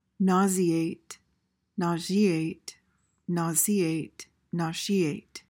PRONUNCIATION:
(NAW-zee/zhee/see/shee-ayt)